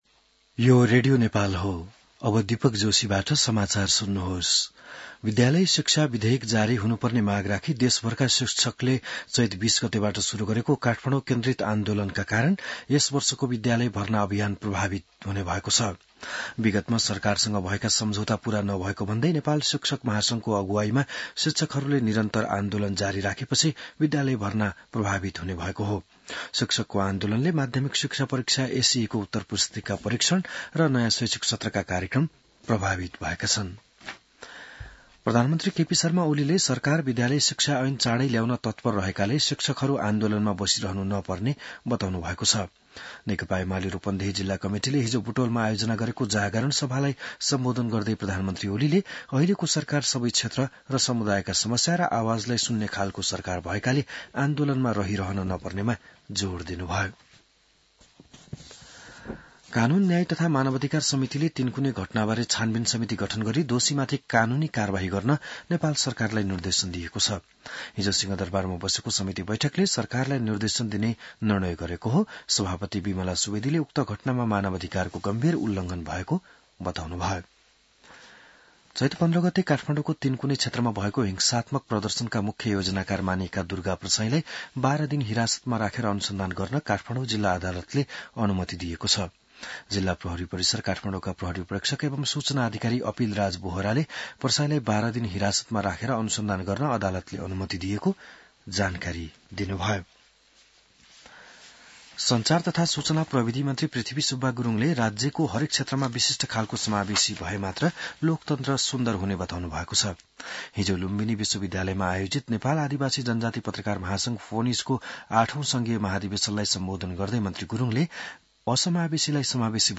बिहान १० बजेको नेपाली समाचार : ३० चैत , २०८१